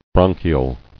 [bron·chi·al]